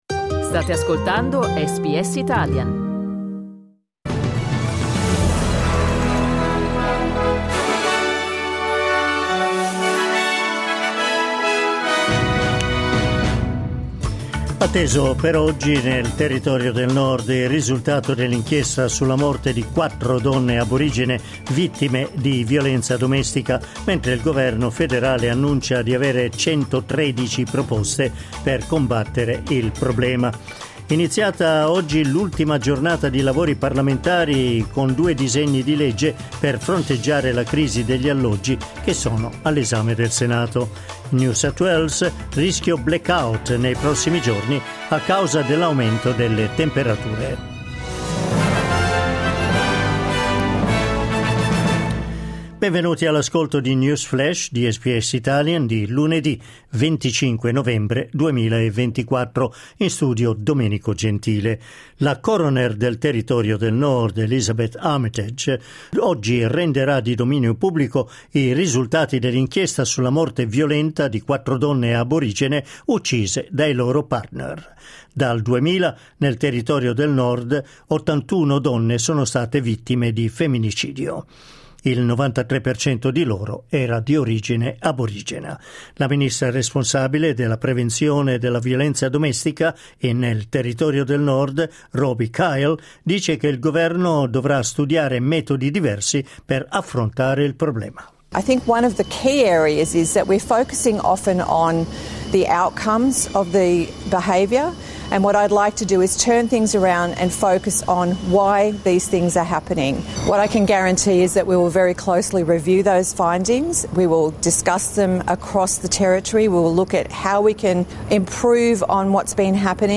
News flash lunedì 25 novembre 2024